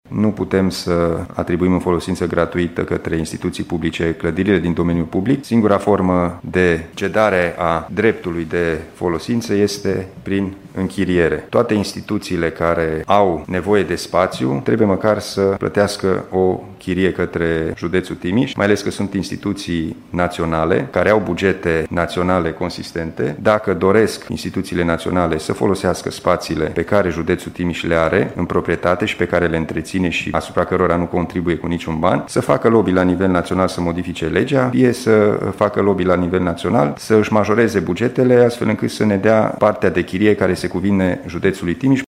Instituțiile și-au desfășurat încă din 2016 activitatea în acest sediu, fără să plătească niciun ban. Acest lucru nu mai este posibil acum, potrivit codului administrativ, spune președintele CJ Timiș, Alin Nica.